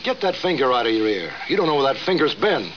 airplane-finger.wav